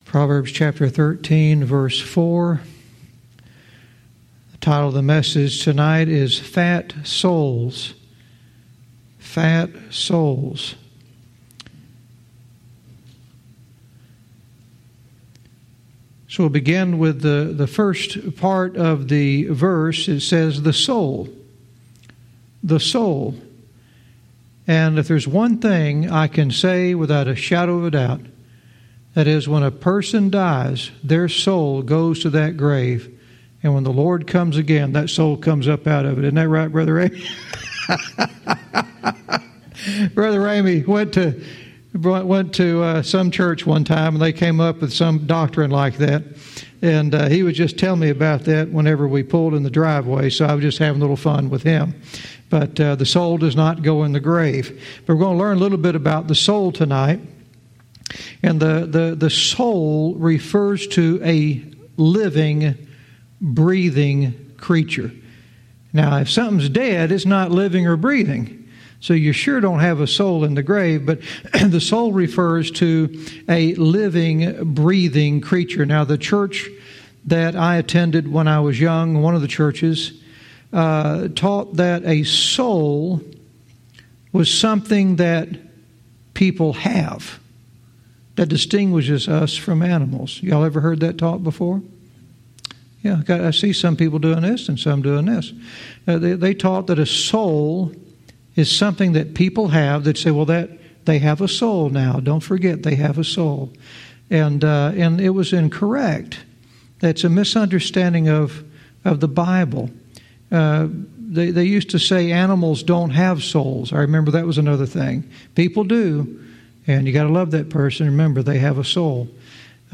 Verse by verse teaching - Proverbs 13:4 "Fat Souls"